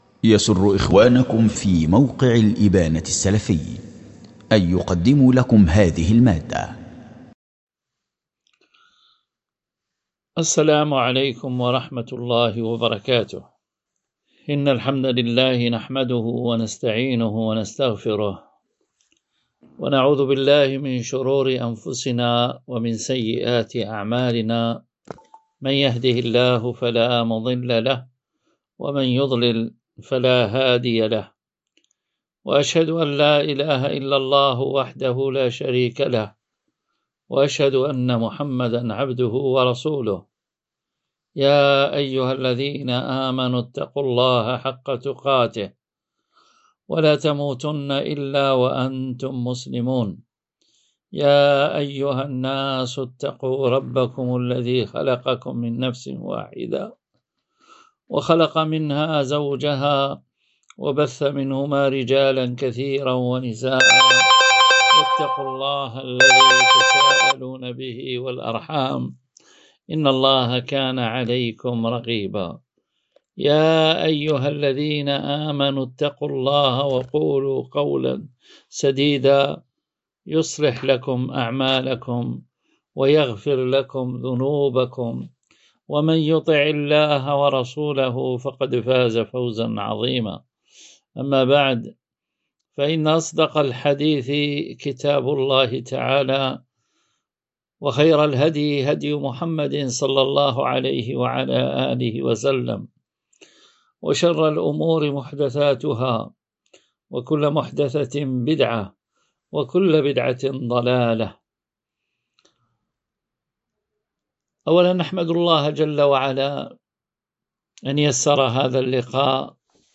🔹للإخوة بالإقامة الجامعية 🔹 بمدينة باتنة 🗓 ليلة الأربعاء 15 جمادى الأولى 1447 هـ الموافق لـ5 نوفمبر 2025 م
دروس ومحاضرات